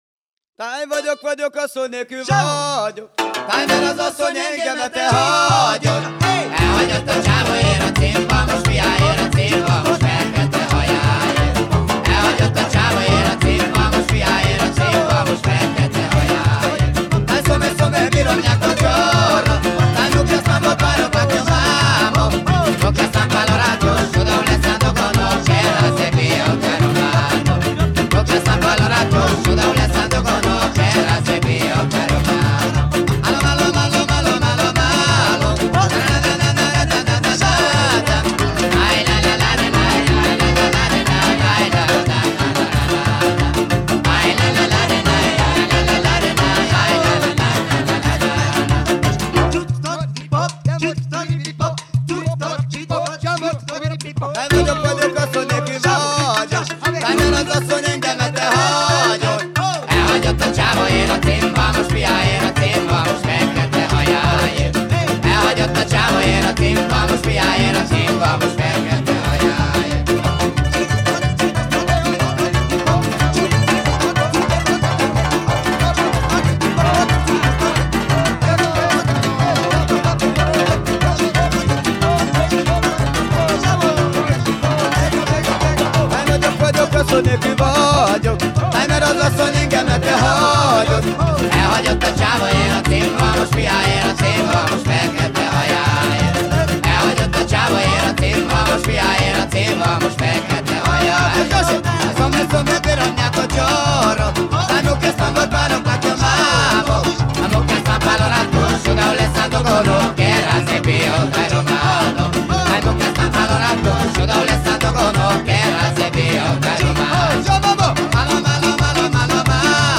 Музыка народов мира